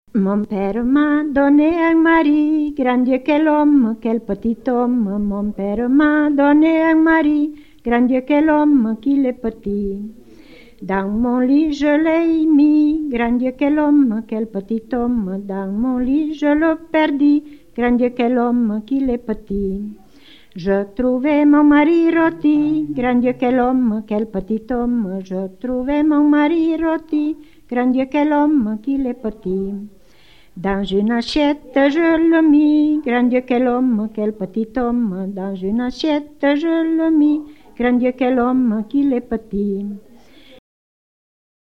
Il maritino / [registrata a Pomaretto (TO)